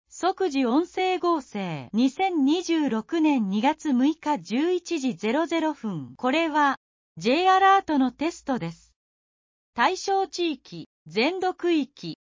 「即時音声合成」 2026年02月06日11時00分 これは、Jアラートのテストです。